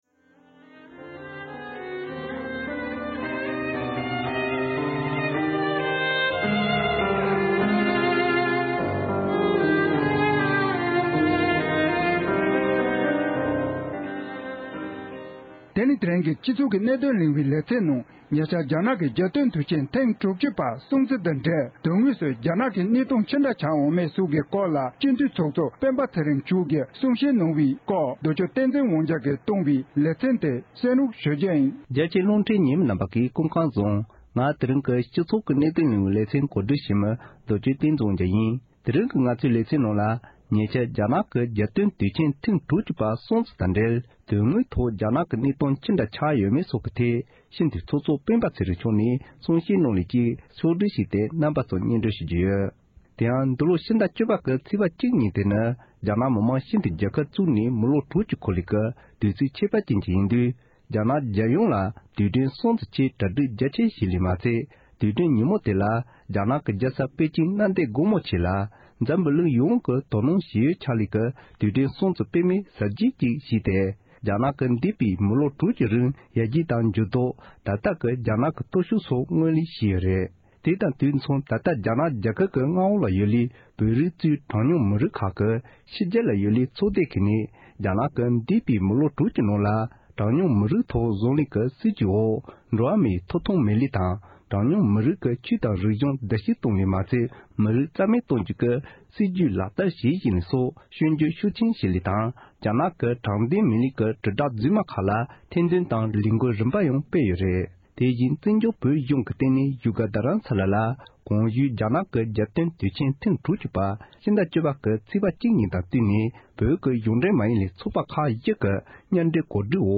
བོད་མི་མང་སྤྱི་འཐུས་ཚོགས་གཙོ་མཆོག་གིས་རྒྱ་ནག་གི་གནས་སྟངས་ཅི་འདྲྲ་ཞིག་ཆགས་ཡོད་མེད་ཐད་གསུང་བཤད་གནང་ཡོད་པ།
སྒྲ་ལྡན་གསར་འགྱུར། སྒྲ་ཕབ་ལེན།